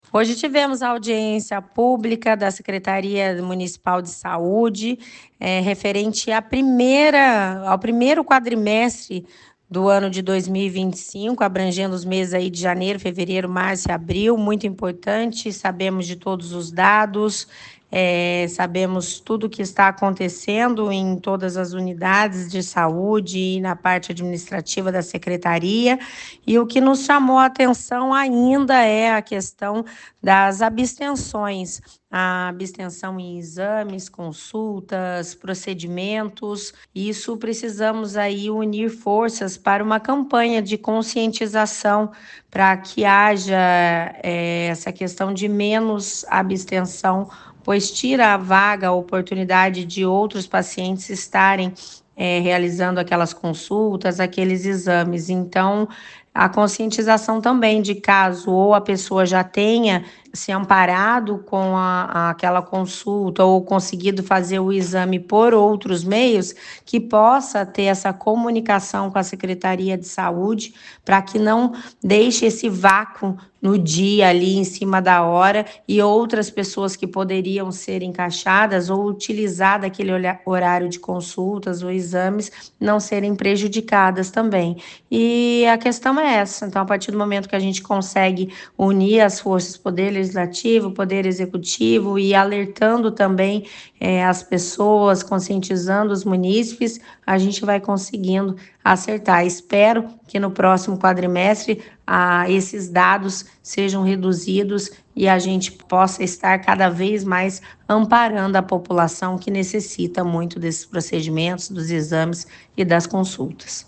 Números foram apresentados, durante audiência pública de prestação de contas do setor, realizada na Câmara
Áudio da secretária Municipal de Saúde, Denise Bueno